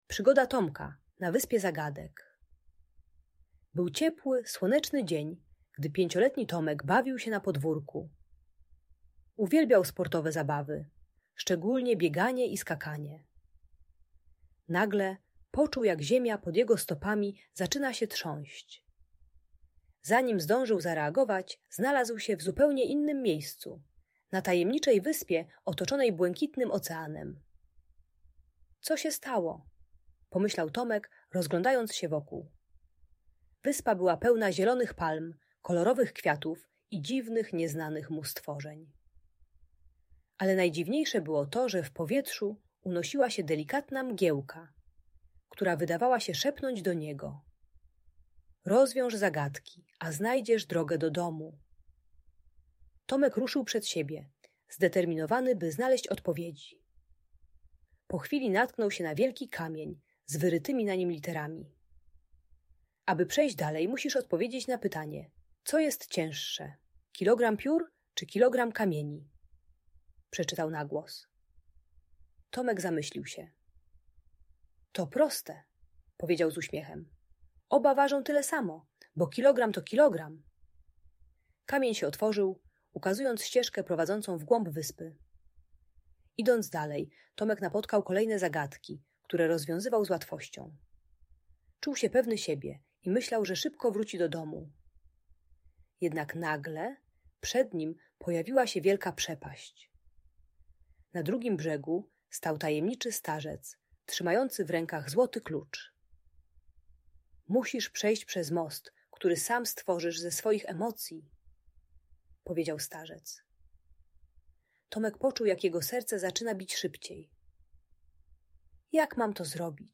Przygoda Tomka na Wyspie Zagadek - Audiobajka dla dzieci